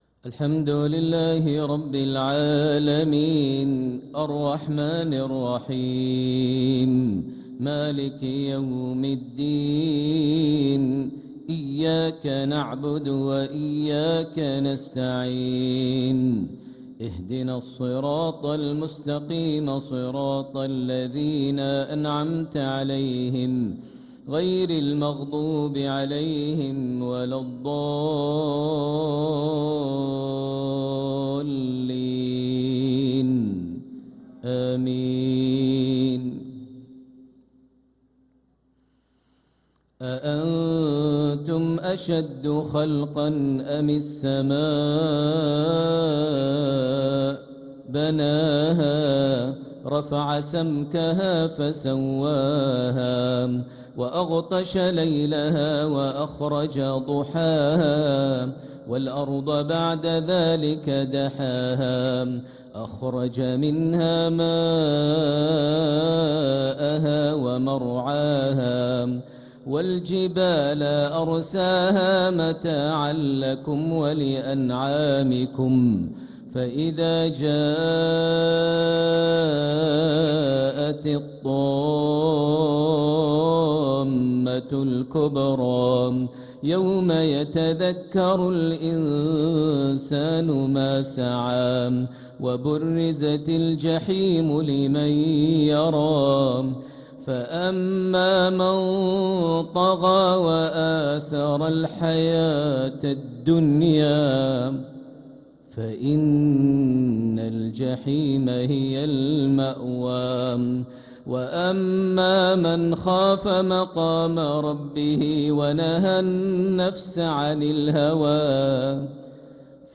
صلاة المغرب للقارئ ماهر المعيقلي 5 ربيع الأول 1446 هـ
تِلَاوَات الْحَرَمَيْن .